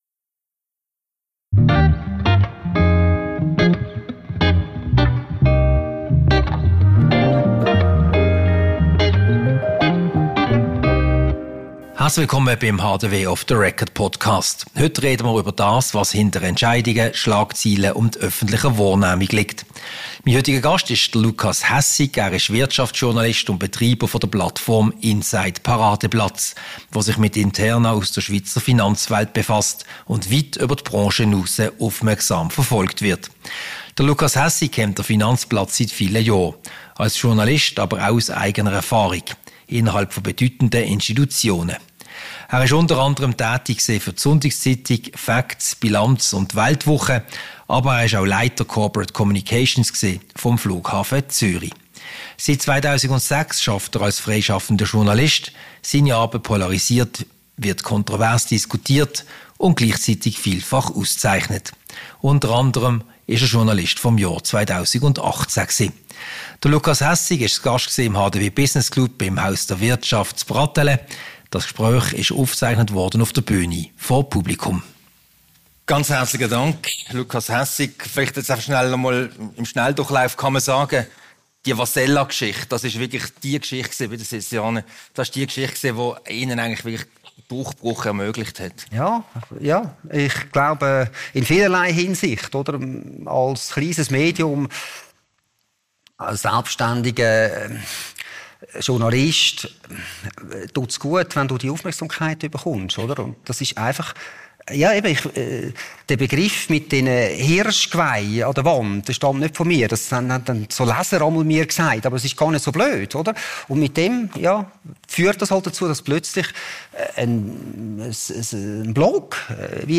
Ein Gespräch über das, was hinter Entscheidungen, Schlagzeilen und öffentlicher Wahrnehmung liegt.